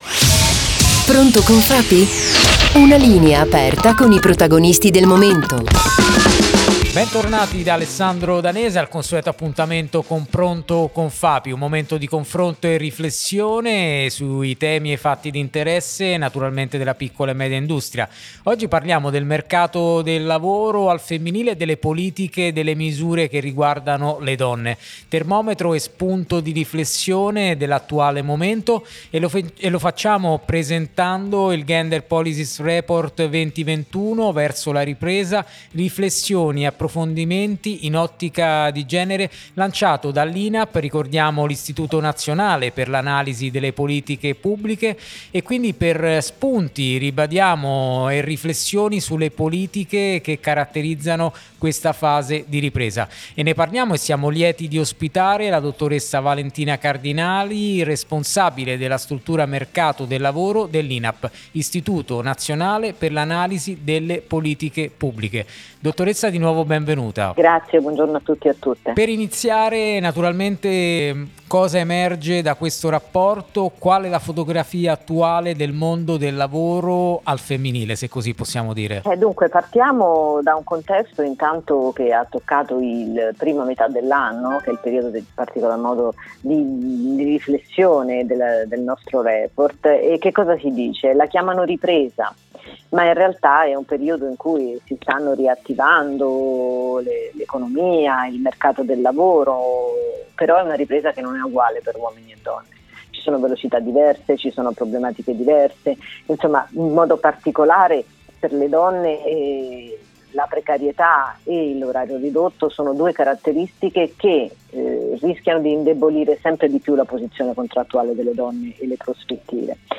Gender Policies Report: intervista